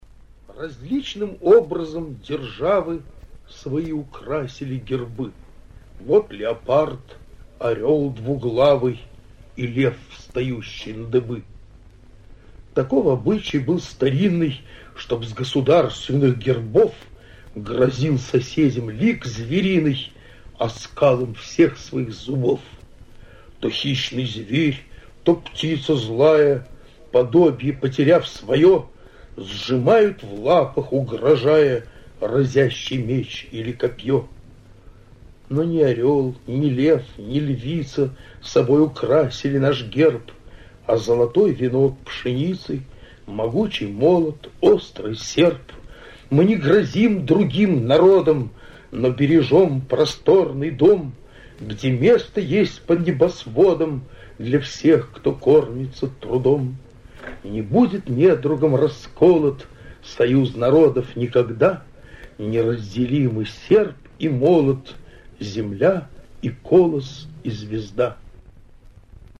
Исполняет: читает автор